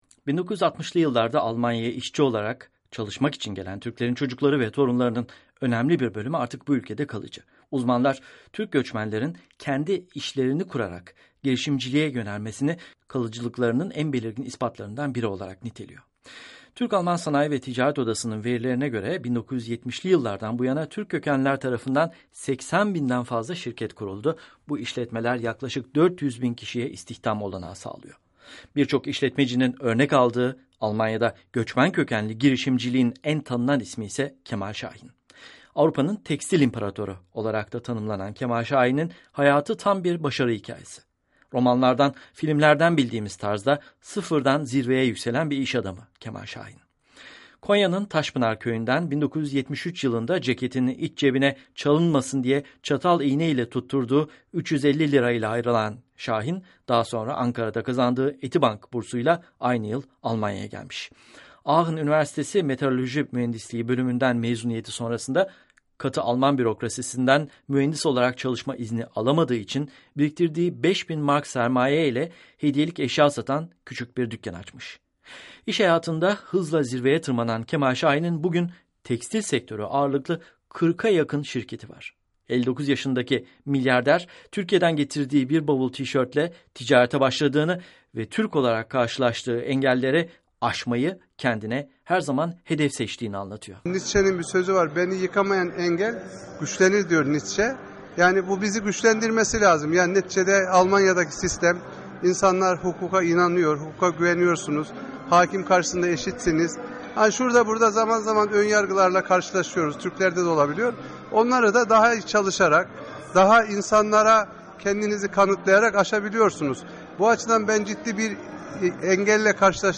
söyleşisi